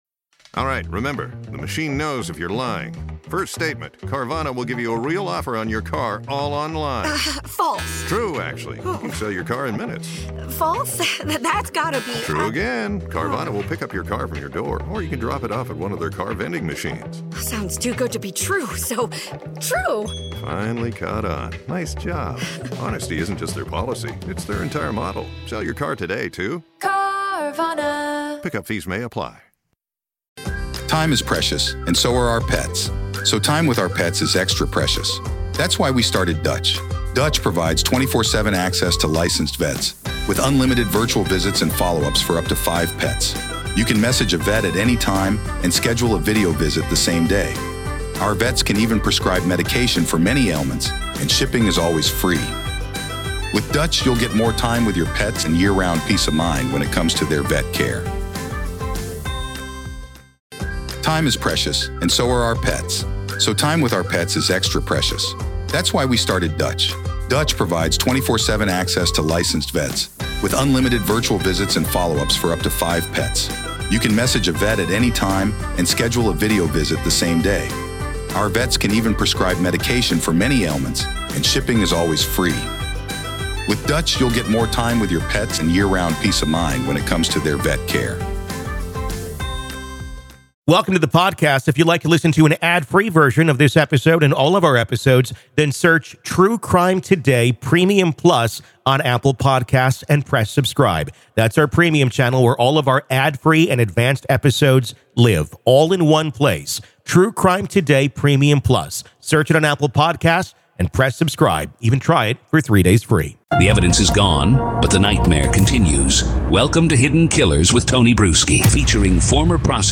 True Crime Today | Daily True Crime News & Interviews / Was There Someone On The Inside In NOLA That Helped Enable Jan 1 Attack?